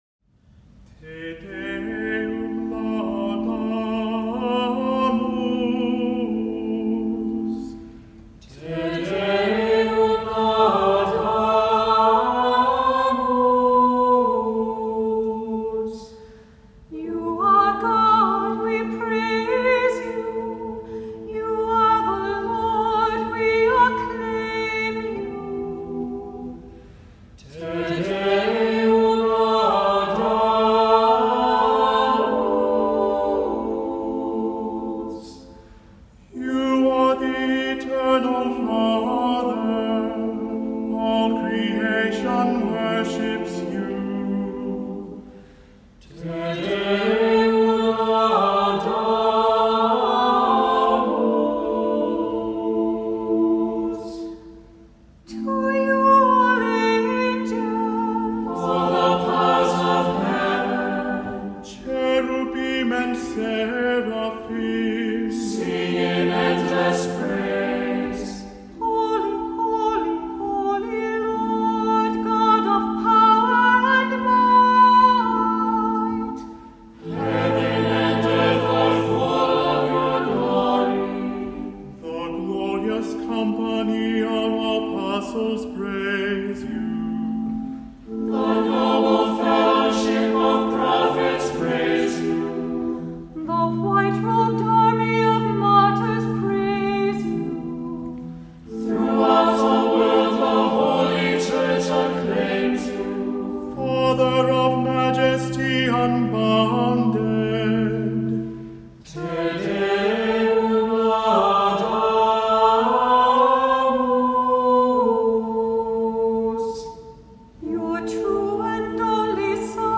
Voicing: SATB; 2 Cantors; Assembly